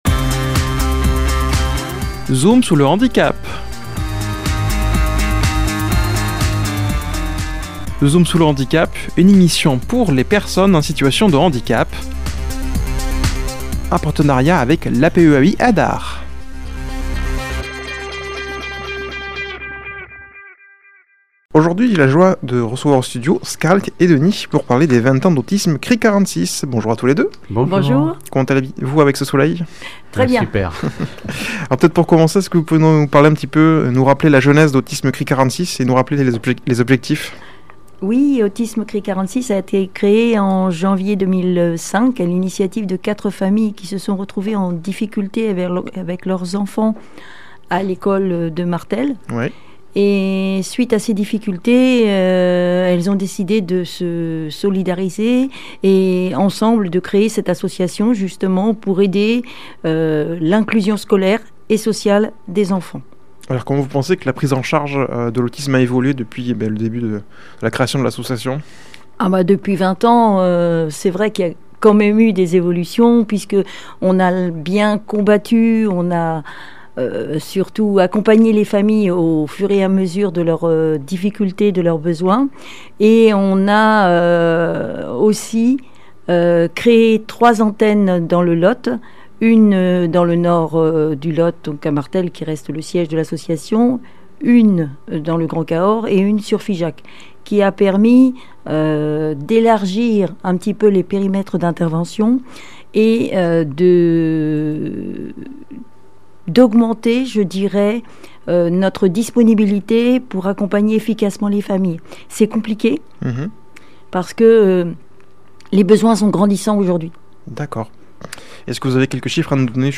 a comme invités au studio